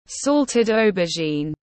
Cà muối tiếng anh gọi là salted aubergine, phiên âm tiếng anh đọc là /ˈsɒl.tɪd ˈəʊ.bə.ʒiːn/